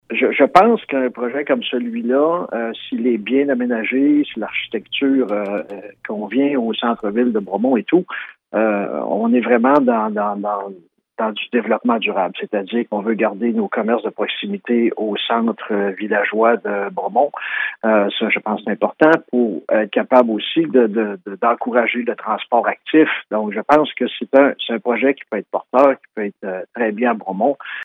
En entrevue sur nos ondes, le maire Louis Villeneuve, a rappelé que le secteur visé est zoné commercial depuis 2003 et voit l’arrivée de ces commerces d’un bon œil :